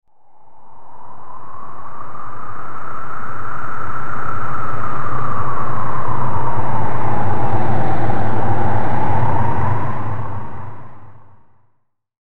دانلود آهنگ باد 5 از افکت صوتی طبیعت و محیط
دانلود صدای باد 5 از ساعد نیوز با لینک مستقیم و کیفیت بالا
جلوه های صوتی